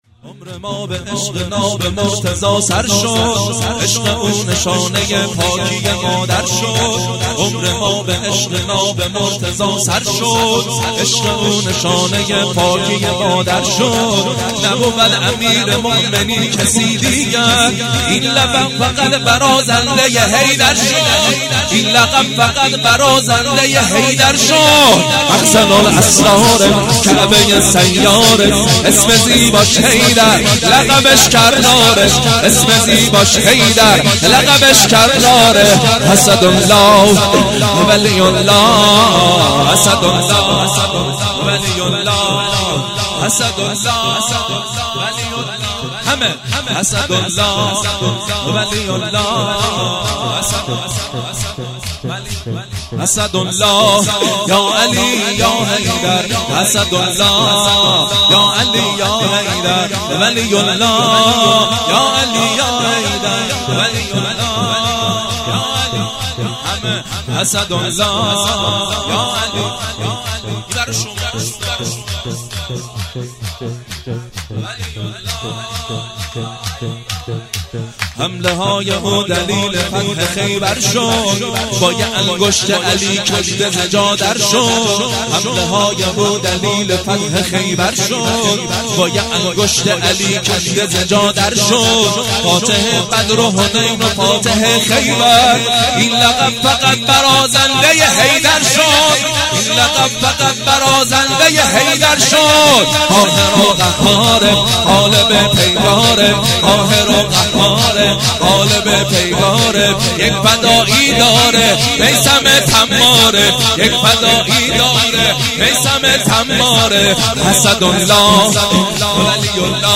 0 0 شور - عمر ما به عشق ناب مرتضی سر شد
جشن مبعث